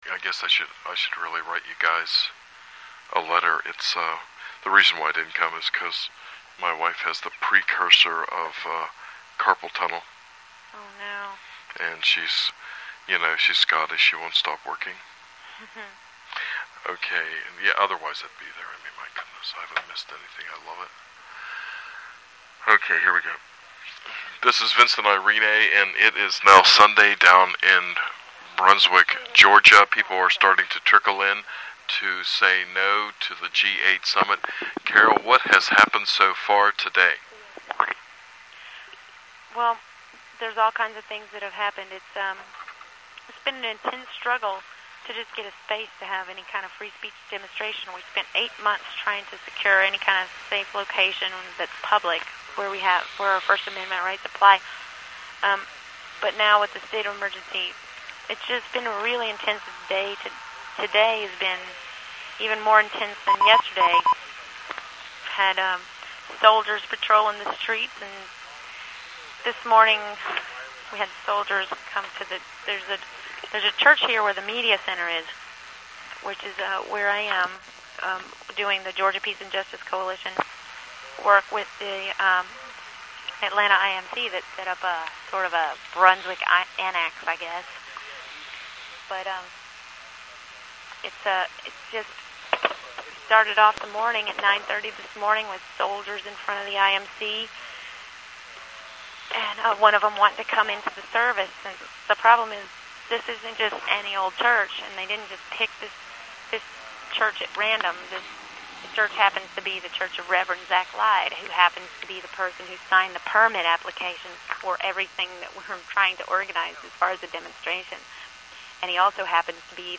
#3 of the "NO G8" PHONE INTERVIEWS : Pittsburgh Indymedia